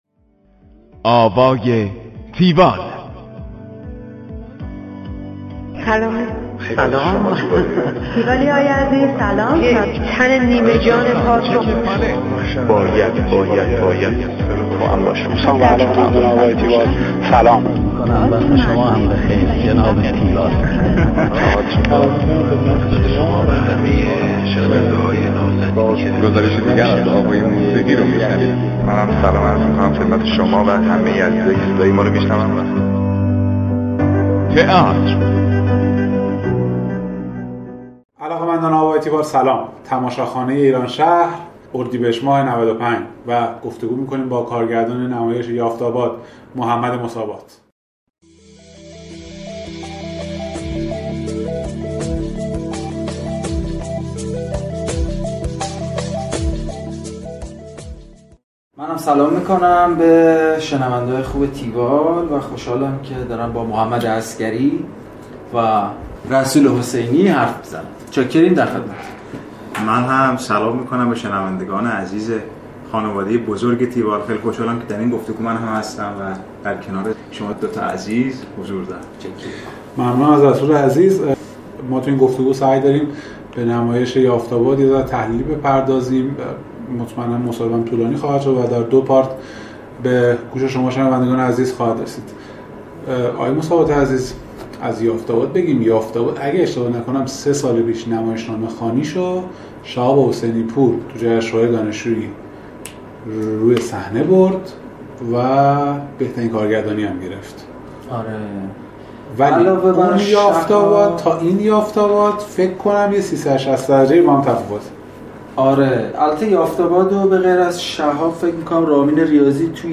گفتگوی تحلیلی تیوال